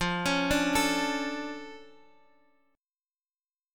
F Augmented 6th
F+6 chord {x x 3 6 3 5} chord